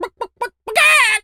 chicken_cluck_to_scream_03.wav